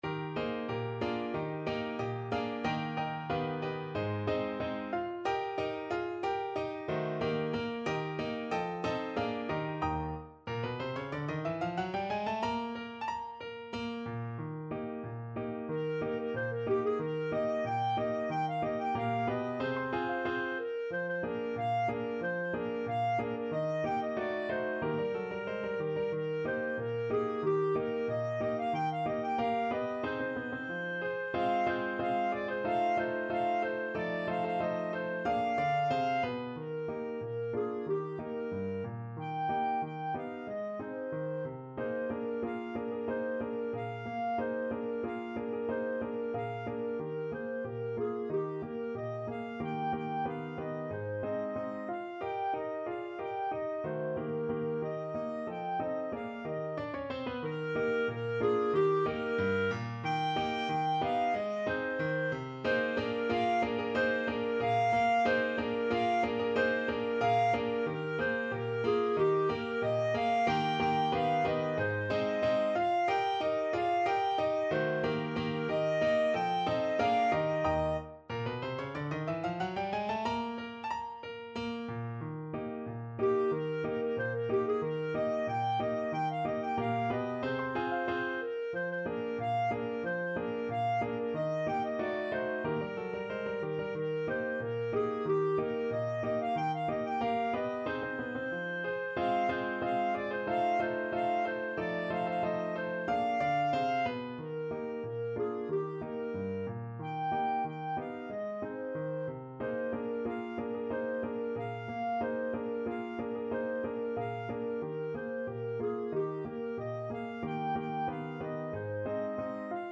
2/4 (View more 2/4 Music)
Allegretto =92
Traditional (View more Traditional Clarinet Music)